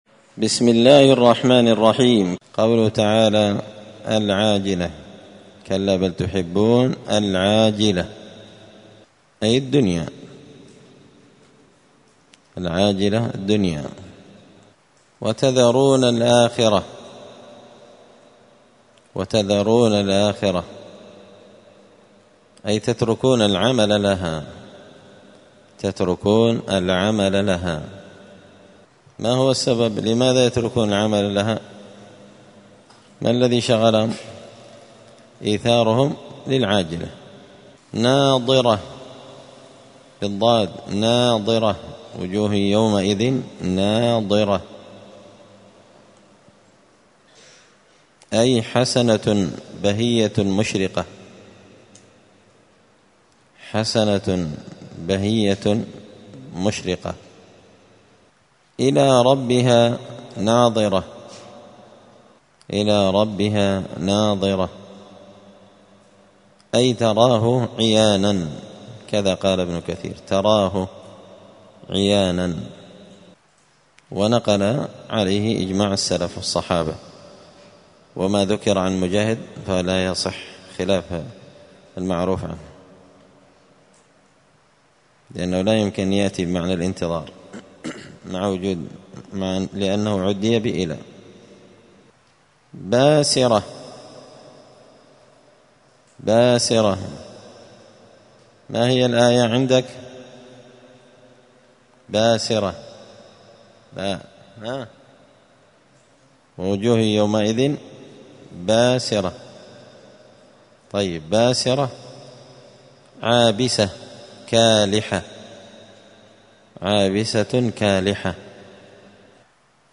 105الدرس-الخامس-بعد-المائة-من-كتاب-زبدة-الأقوال-في-غريب-كلام-المتعال.mp3